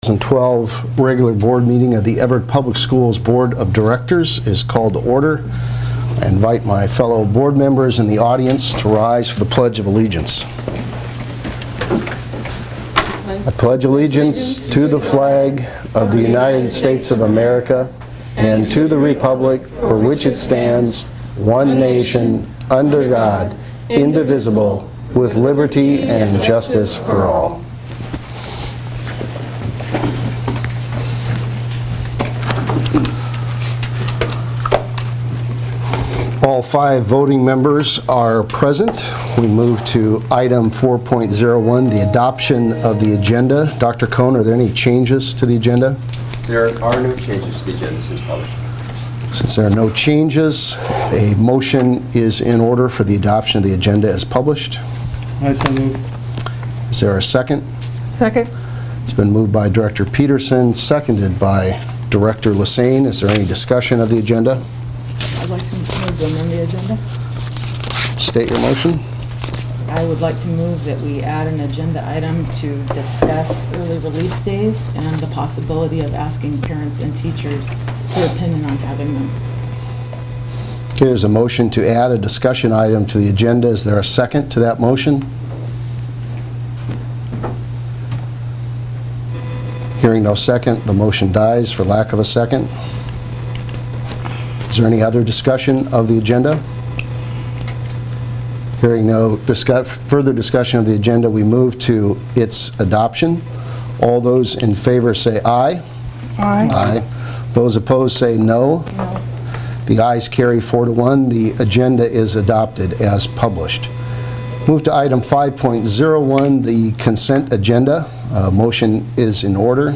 Board Meeting Audio Regular